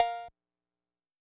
Cmperc2.wav